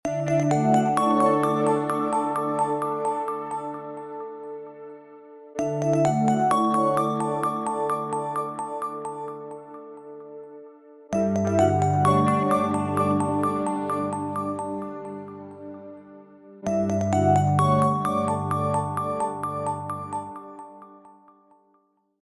Звуки телефона TCL
2. Будильник Starlight